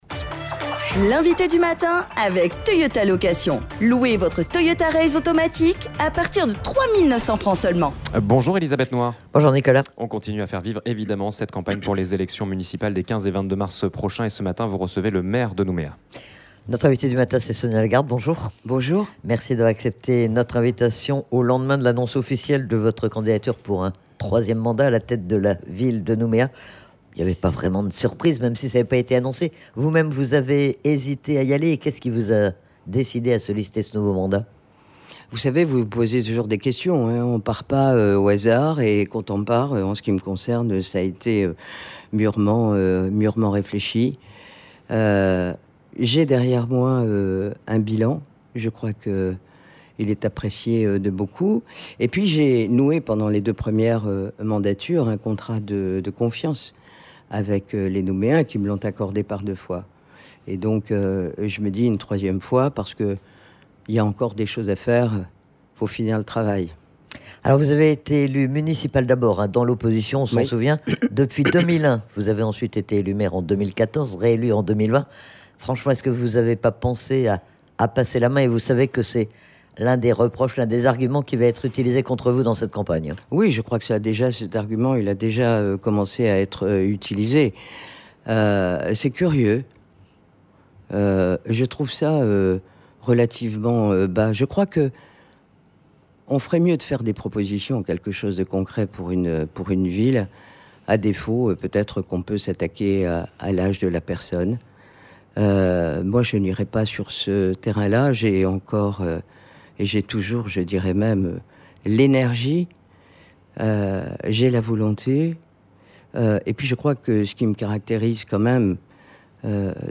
La préparation des municipales. C'est Sonia Lagarde qui était notre invitée du matin, au lendemain de l'annonce de sa candidature pour un troisième mandat à la tête de la ville avec une équipe renouvelée. Elle met en avant trois mots pour présenter sa candidature : Stabilité, solidité et sérieux.